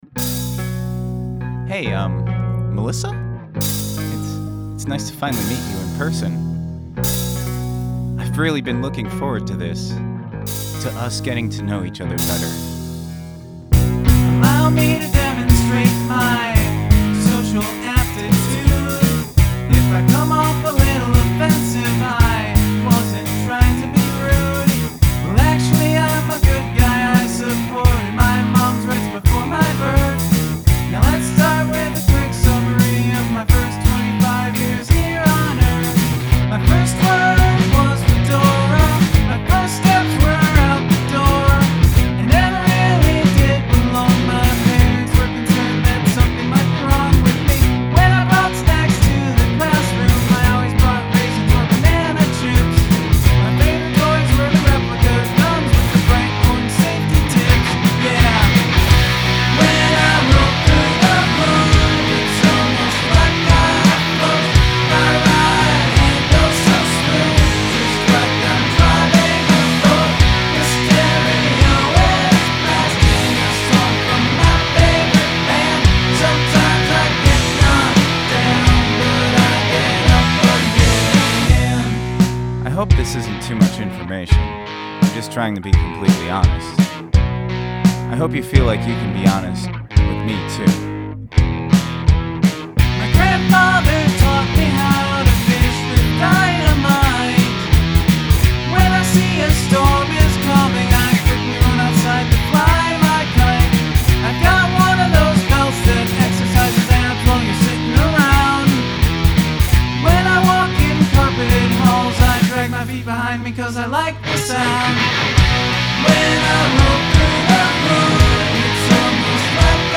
That chorus guitar tone is a nostalgia blast.